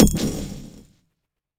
Impact 23.wav